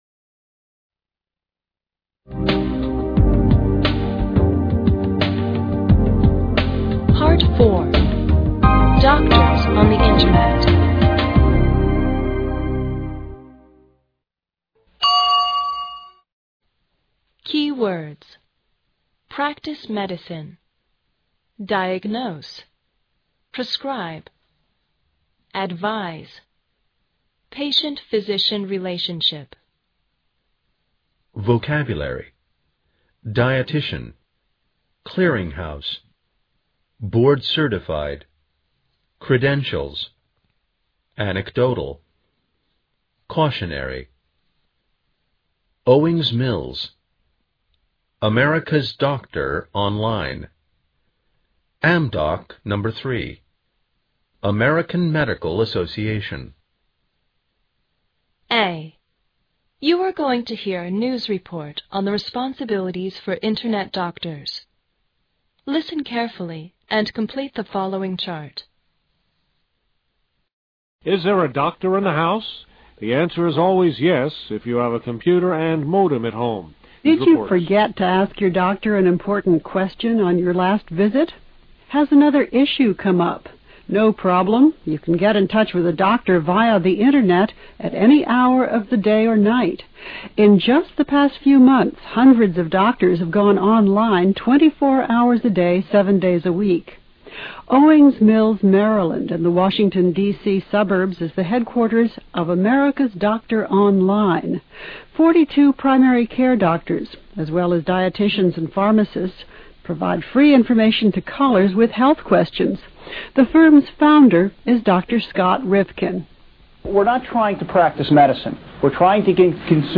A. You're going to hear a news report on the responsibilities for internet doctors.